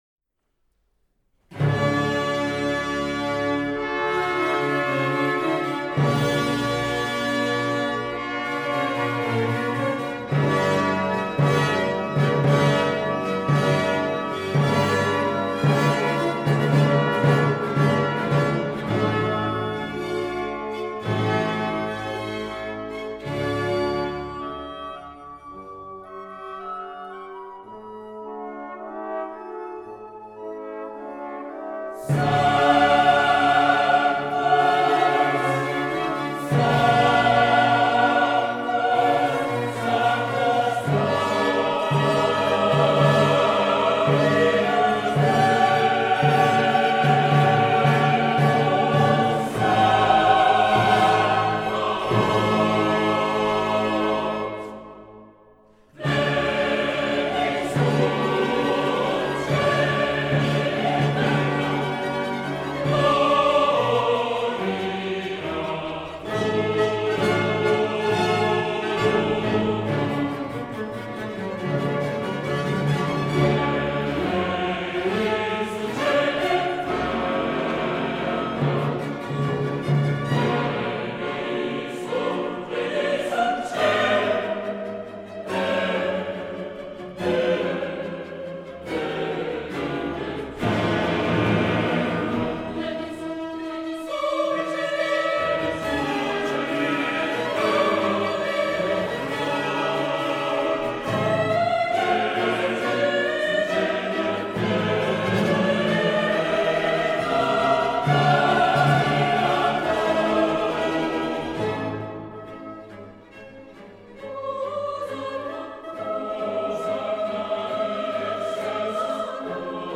Coro della Radio Svizzera. I Barocchisti. Diego Fasolis, cond.